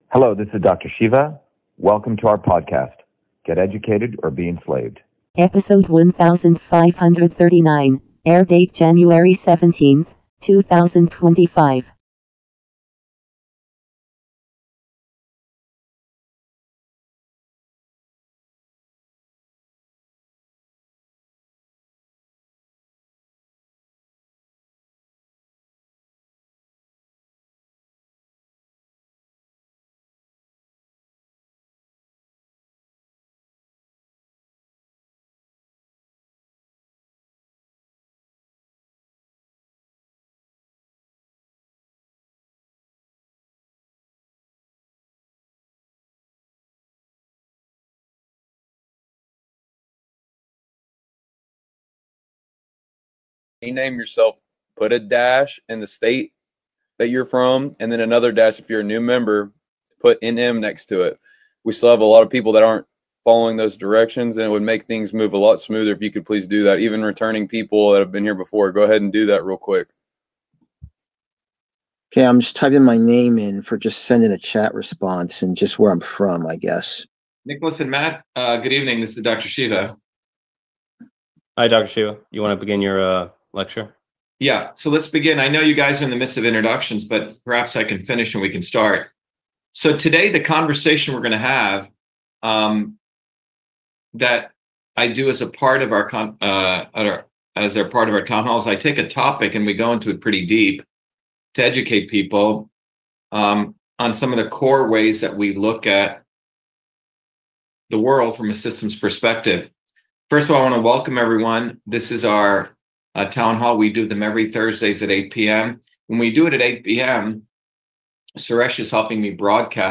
In this interview, Dr.SHIVA Ayyadurai, MIT PhD, Inventor of Email, Scientist, Engineer and Candidate for President, Talks about Why We Must Seize the Means of Production.